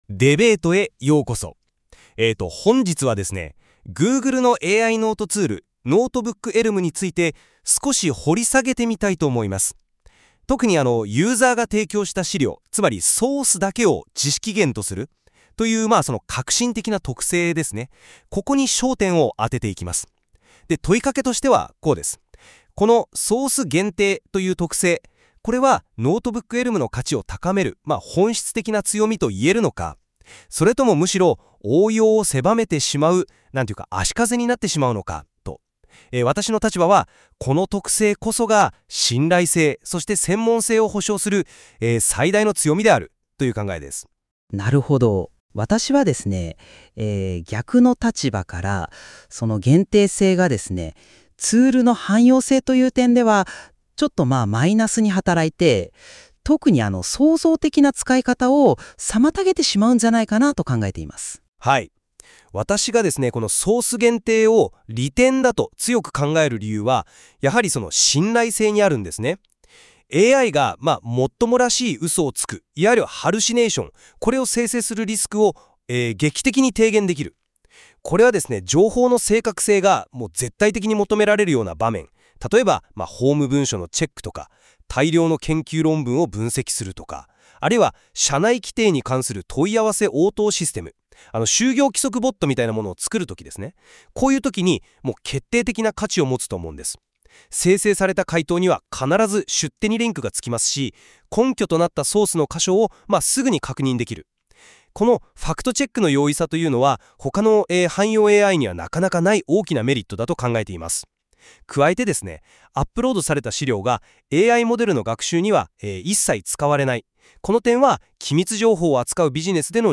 ※Google の AI「NotebookLM」で生成した対話形式の音声解説です。